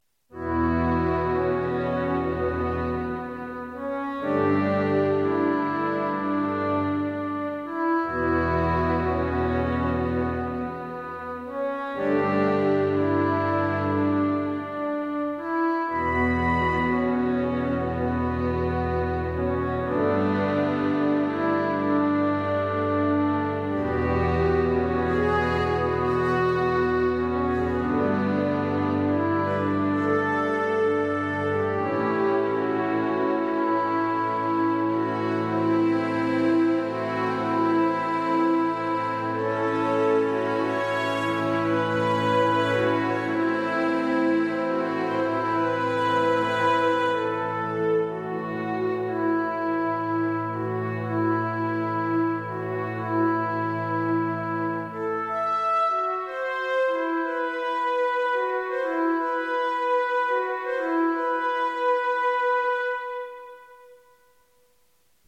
Short Theme Tune
All done on a Korg Kronos 88